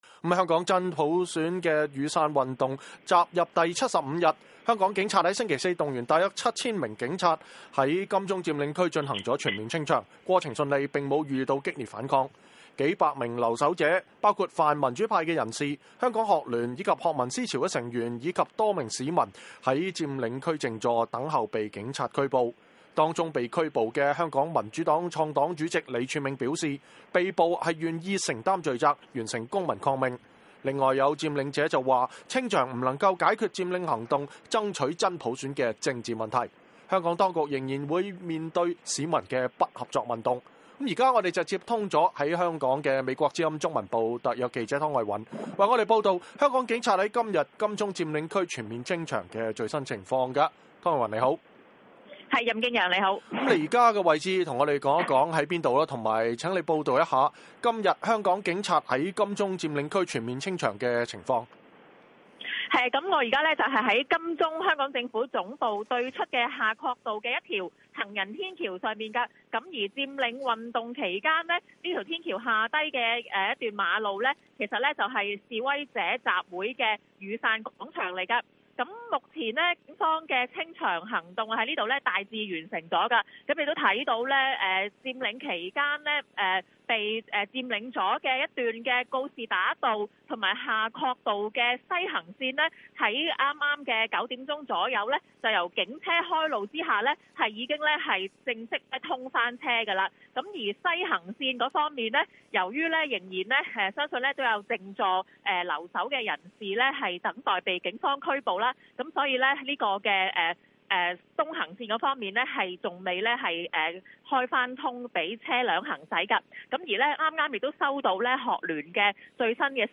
現場連線﹕香港金鐘佔領區清場 佔領者稱將堅持不合作運動